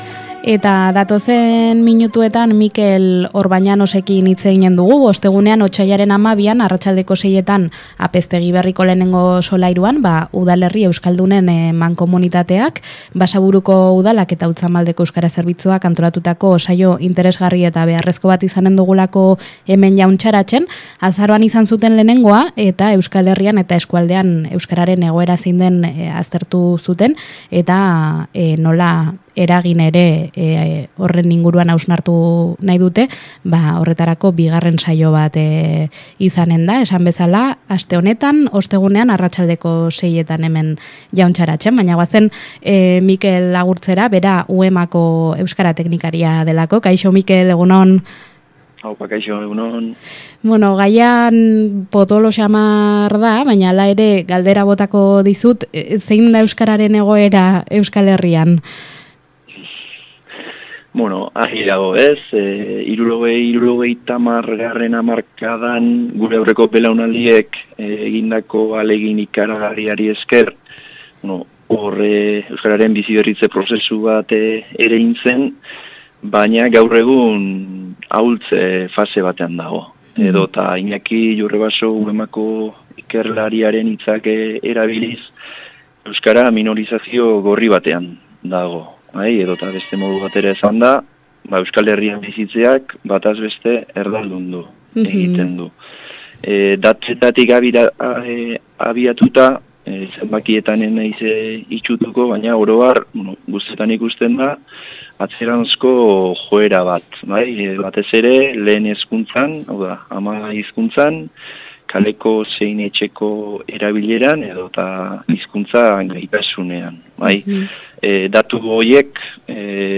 Elkarrizketak